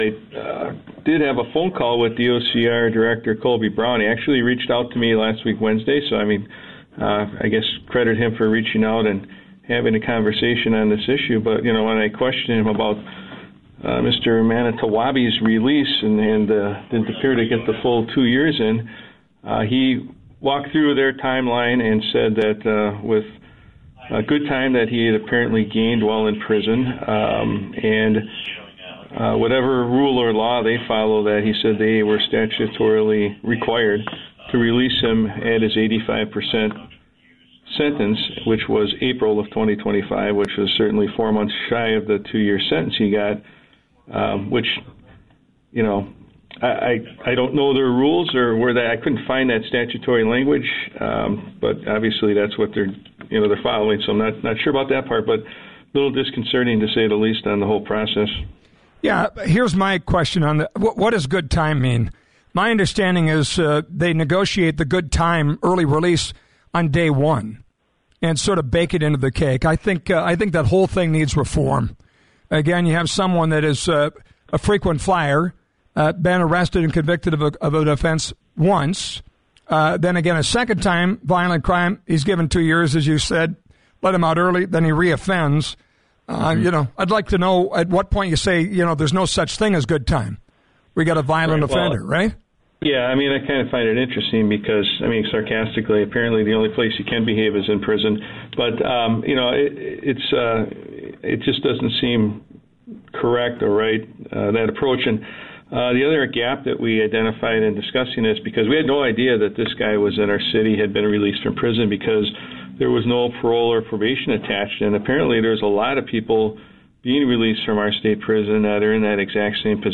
Listen:  Fargo Police Chief David Zibolski appears on The Flag’s ‘What’s On Your Mind?’